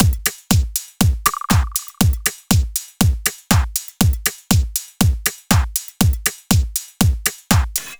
Session 14 - Mixed Beat 01.wav